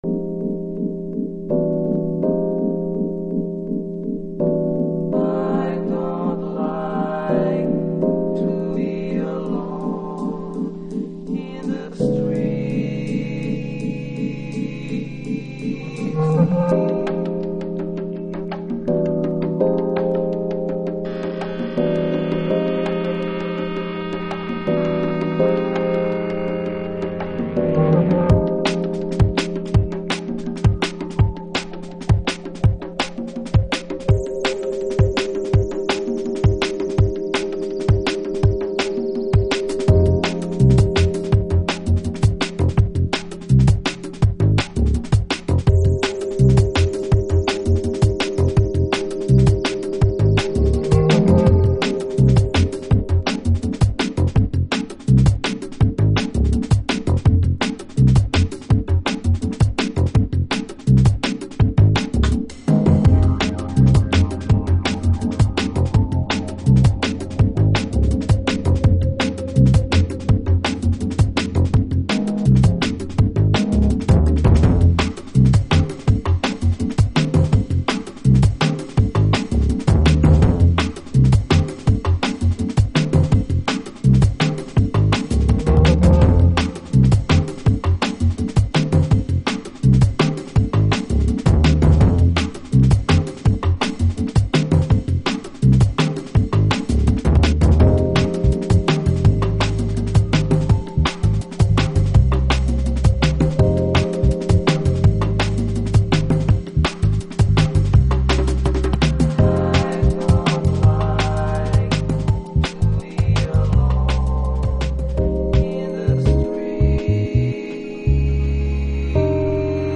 Future Jazz / Broken beats
雅楽を意識した旋律も粋ですね。LTJ Bukem以降のジャズ的なるドラムンベースも収録。